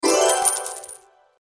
respawn.wav